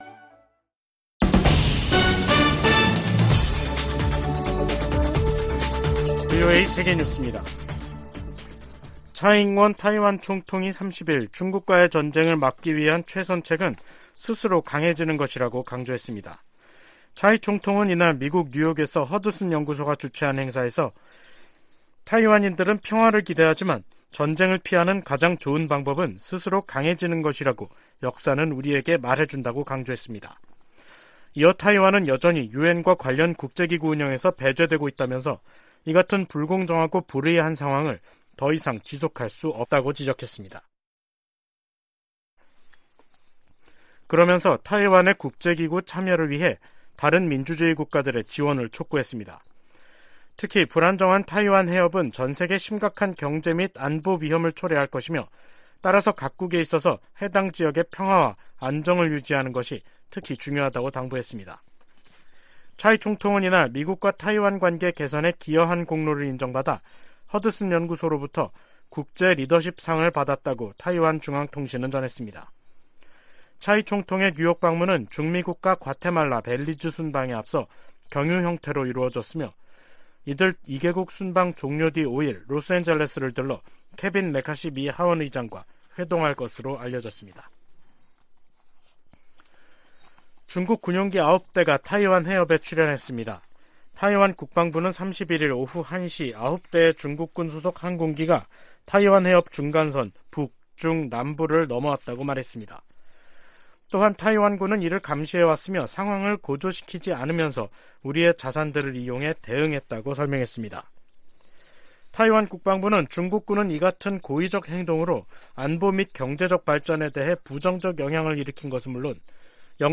VOA 한국어 간판 뉴스 프로그램 '뉴스 투데이', 2023년 3월 31일 3부 방송입니다. 미 재무부가 북한과 러시아의 무기 거래에 관여한 슬로바키아인을 제재 명단에 올렸습니다. 백악관은 러시아가 우크라이나 전쟁에서 사용할 무기를 획득하기 위해 북한과 다시 접촉하고 있다는 새로운 정보가 있다고 밝혔습니다. 미 국방부는 핵무기 한국 재배치 주장에 한반도 비핵화 정책을 계속 유지할 것이라고 밝혔습니다.